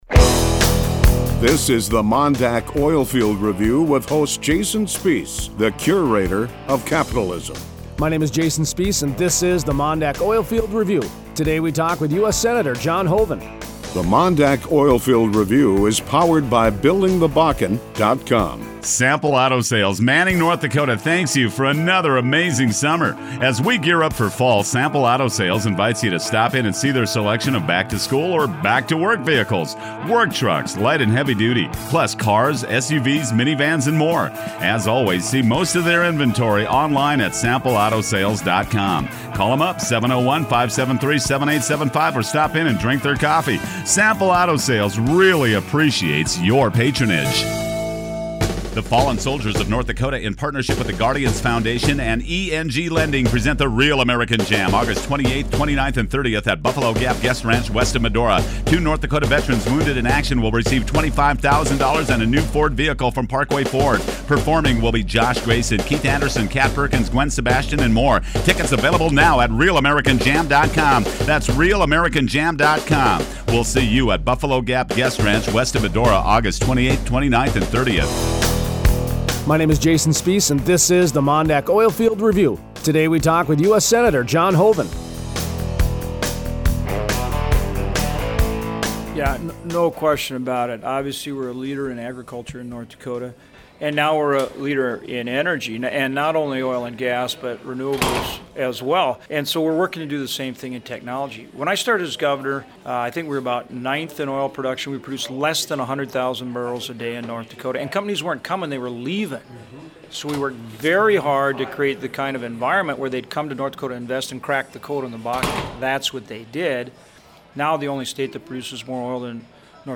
Friday 8/28 Interview: US Senator John Hoeven Talks about the emerging tech sector in oil and gas as well as the investments into small community airports.